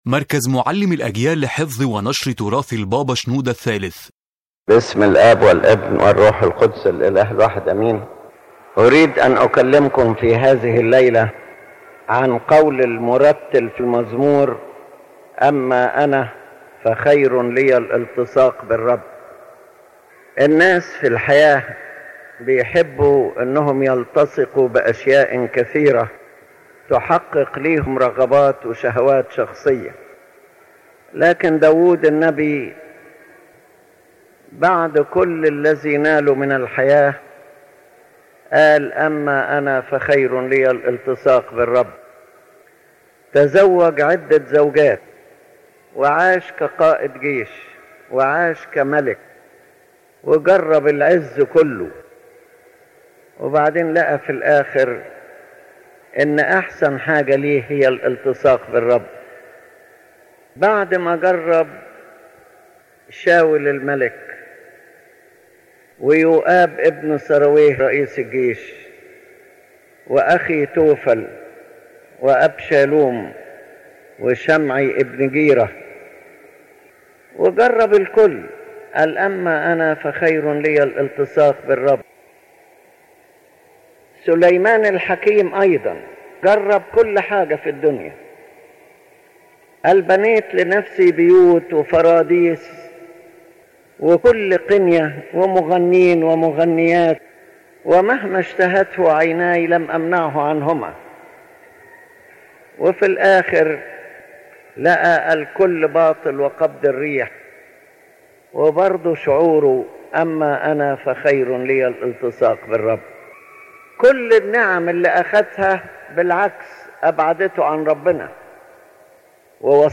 Examples from Scripture and spiritual history The lecture presents examples: David who after all his attainments saw that it is better to cling to the Lord, Solomon who found everything vain, Lot who followed the pleasures of the earth and lost, Samson who wasted his strength and whose end was misery, and other examples of the fathers who left wealth and attachment to the world and gained spiritual benefit.